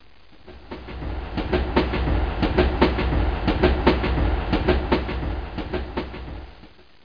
SFXTRAIN.mp3